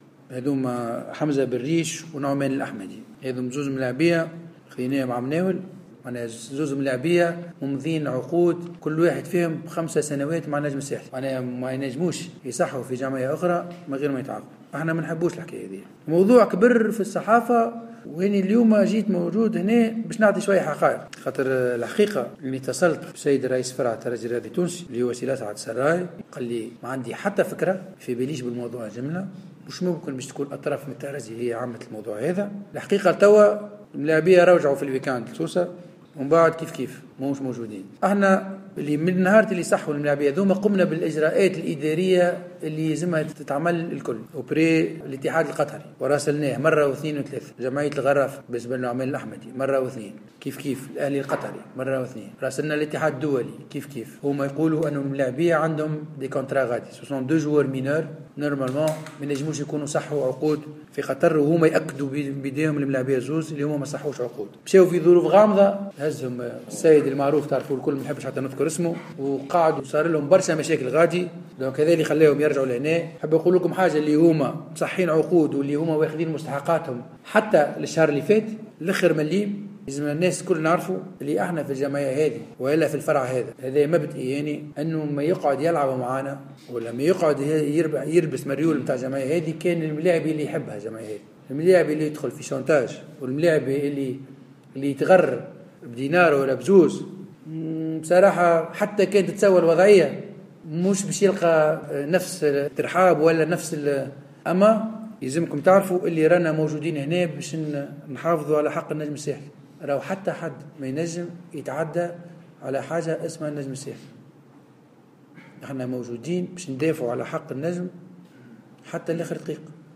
خلال الندوة الصحفية التي عقدها اليوم فرع كرة اليد بمقر الجمعية